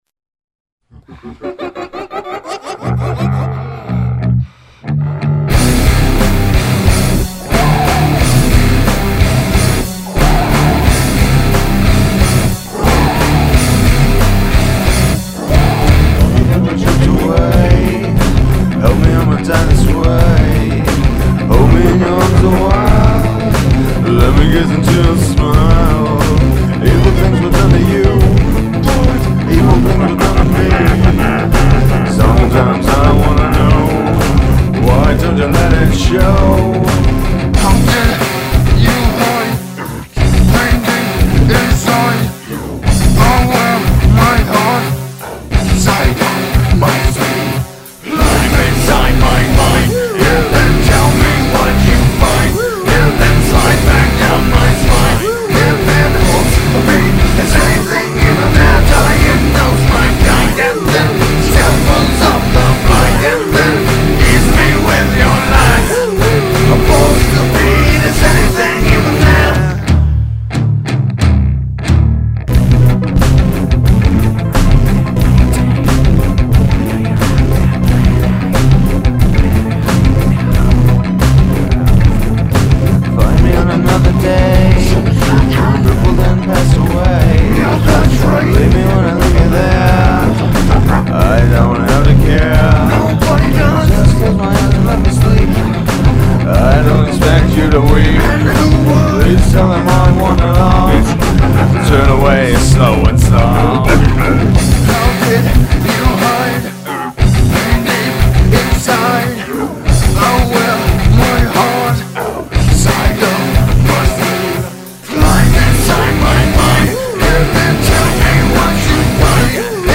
Rock
Perhaps a little vocal tweaking would sound cool.